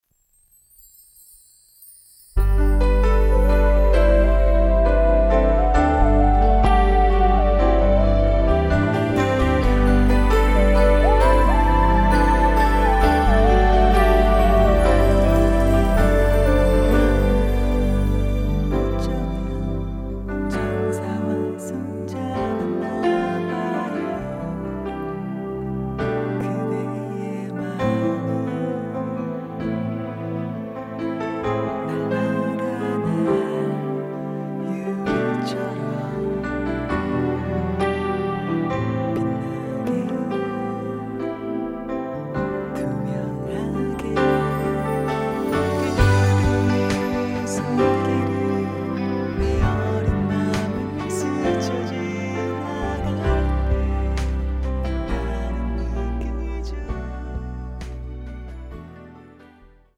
음정 원키 3:39
장르 가요 구분 Voice Cut